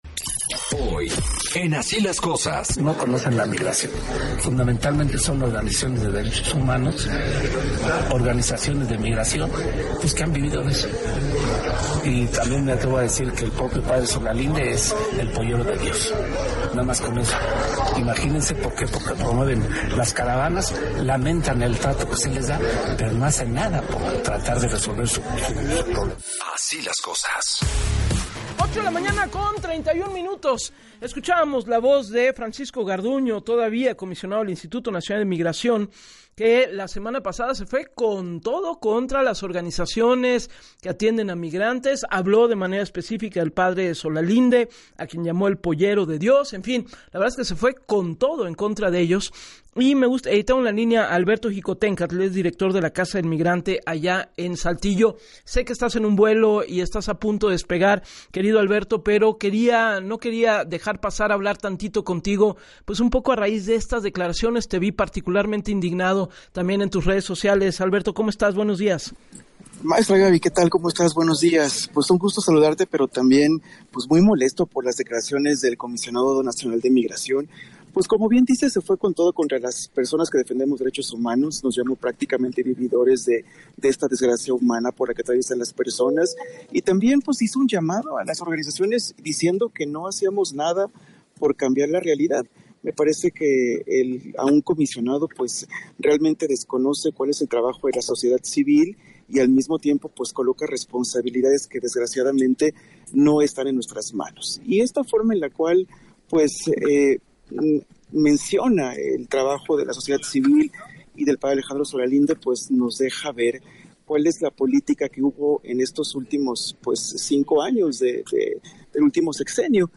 En entrevista para “Así Las Cosas” con Gabriela Warkentin